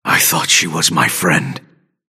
Grey Talon voice line - I thought she was my friend.